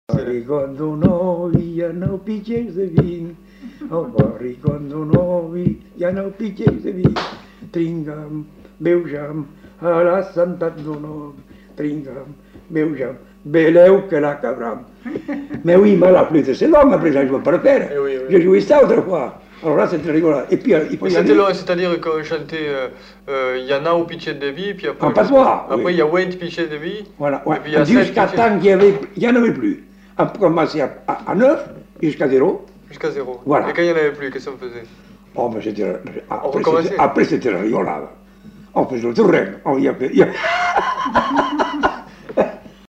Genre : chant
Effectif : 1
Type de voix : voix d'homme
Production du son : chanté
Danse : rondeau